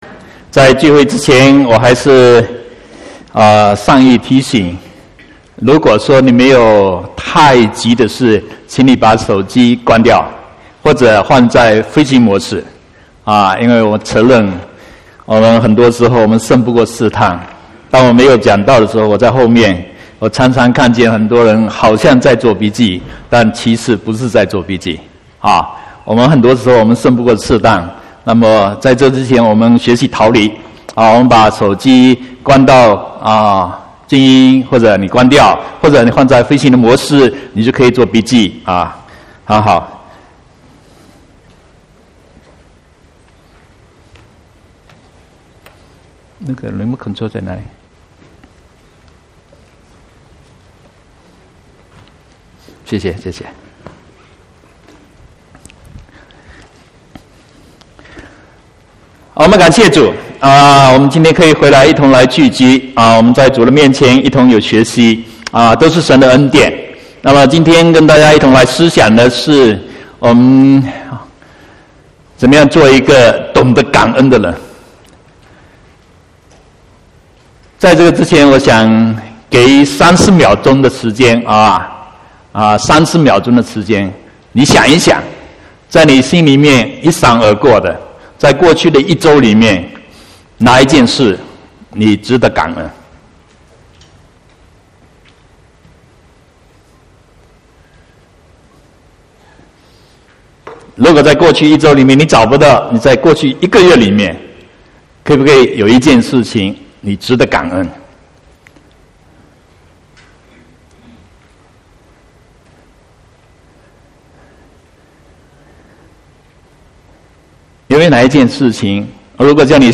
28/1/2018 國語堂講道